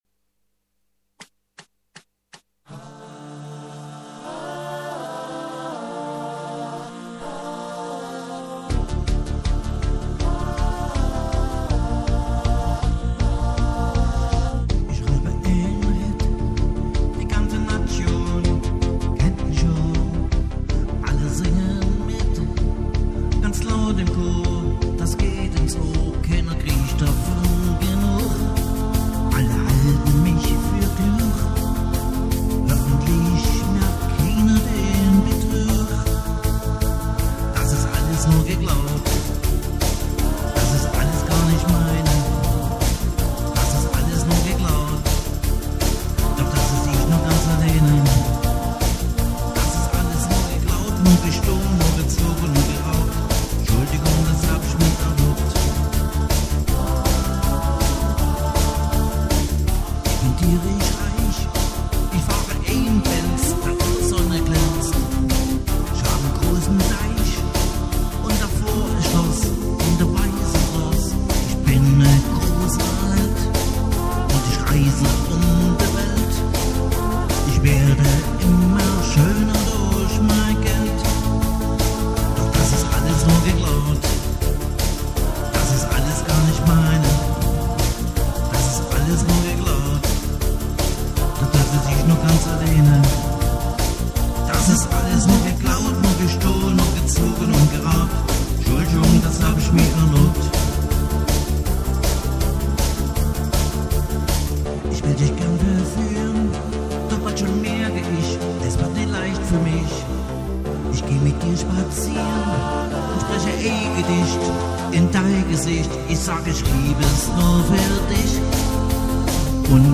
- Livemusik mit Gesang
• Alleinunterhalter